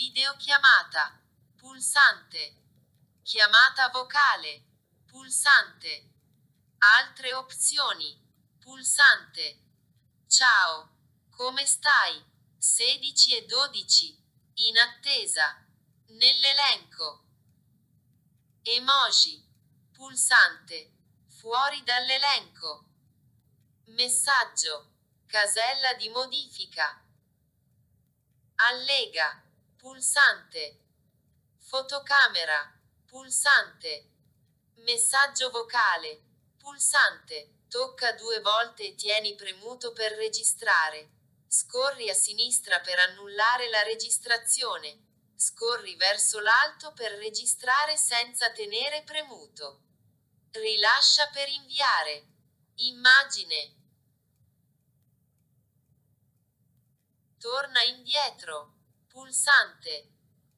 Nota: Gli esempi audio sono relativi a Talkback su Android 12.0, ma con iOS il funzionamento è il medesimo.
Clicca Play di seguito per ascoltare la finestra di una chat di WhatsApp con Talkback.
Talkback-Scorrimento-elementi.mp3